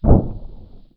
DEMOLISH_Short_07_mono.wav